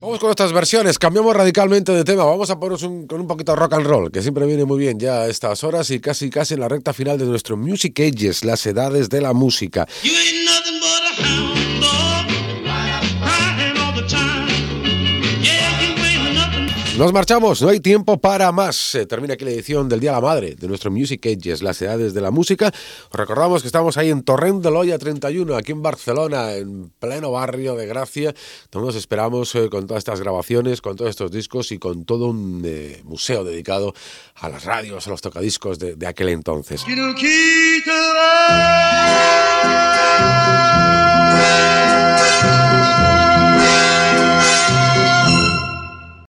Careta del programa i presentació inicial.
Musical